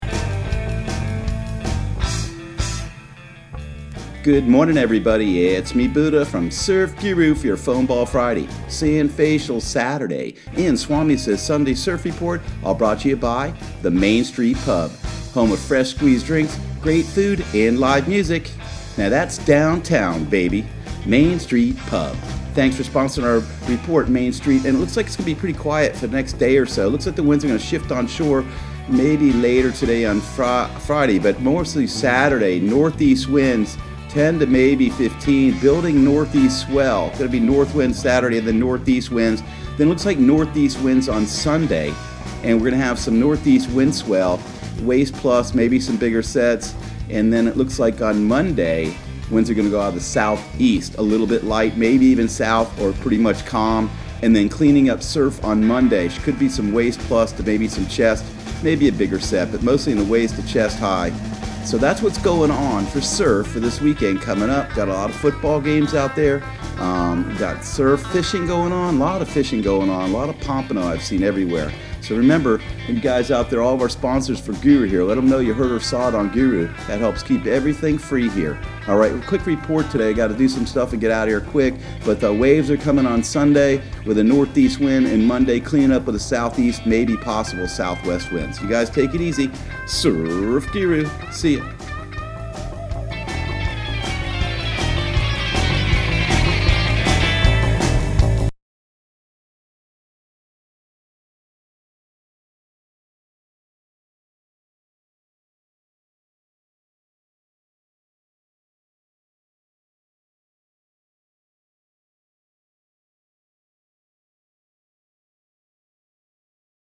Surf Guru Surf Report and Forecast 12/06/2019 Audio surf report and surf forecast on December 06 for Central Florida and the Southeast.